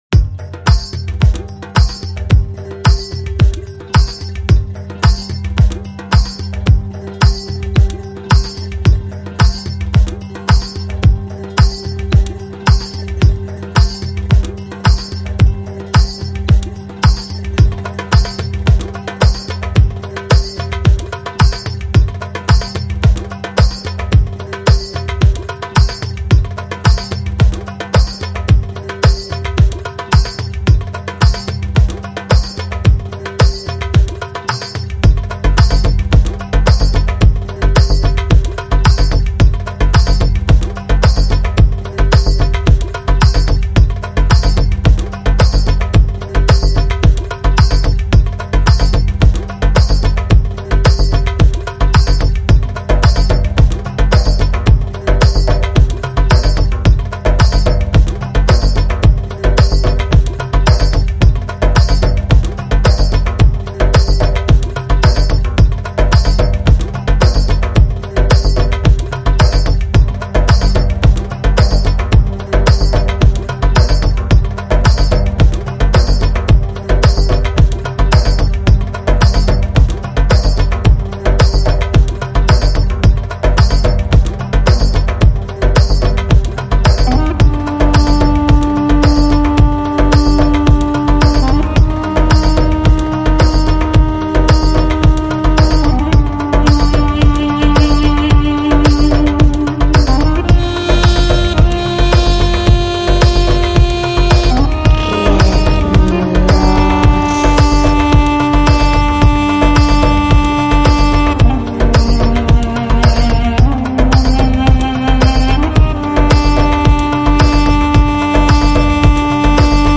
Lounge, Chill Out, Downtempo, Organic House